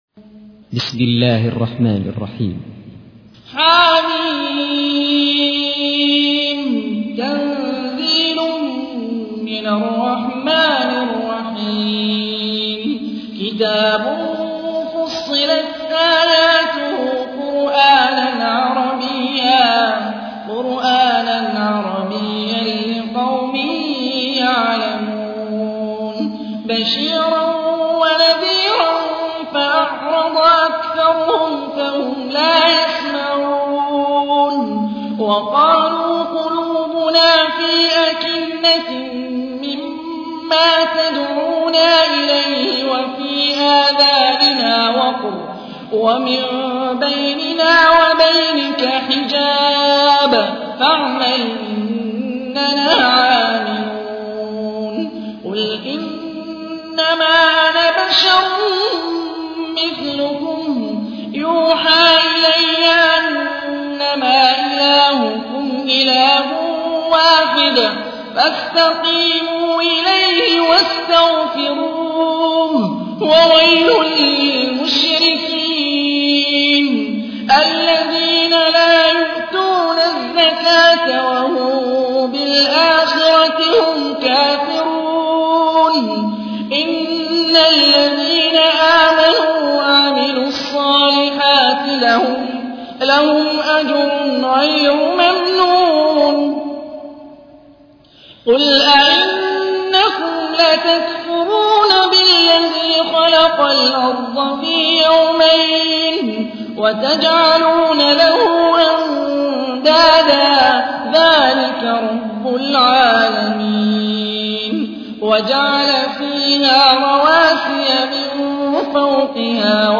تحميل : 41. سورة فصلت / القارئ هاني الرفاعي / القرآن الكريم / موقع يا حسين